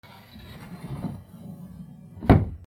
引き戸を閉める
/ K｜フォーリー(開閉) / K05 ｜ドア(扉)
NR 『シュルシュルバタン』